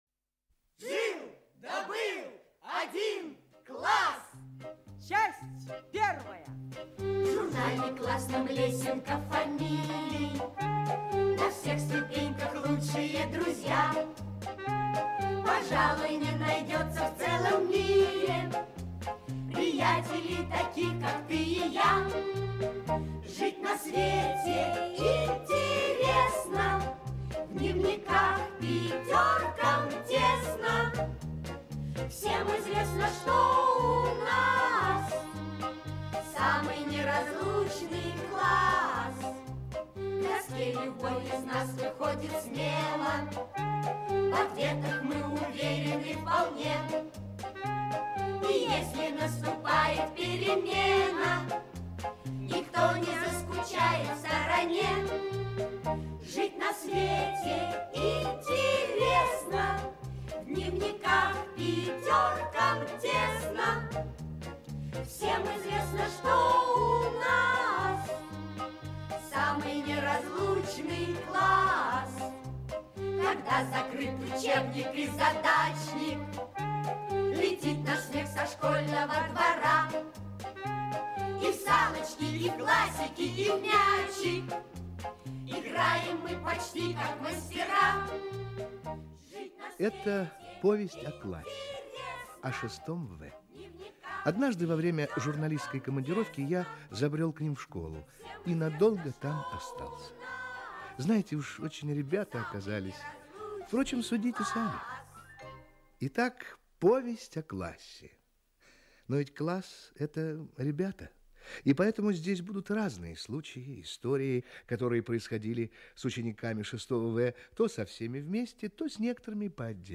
Аудиокнига Жил да был один класс. Часть 1 | Библиотека аудиокниг